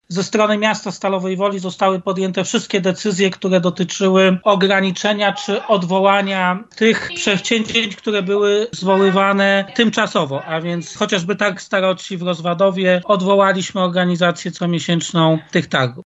Władze Stalowej Woli odwołały Targ Staroci. Taka decyzja zapadła w związku z zagrożeniem rozprzestrzeniania się koronawirusa i zaleceniami o ograniczeniu zgromadzeń. Mówił o tym prezydent Stalowej Woli Lucjusz Nadbereżny podczas wspólnego posiedzenia komisji zdrowia miejskiej i powiatowej: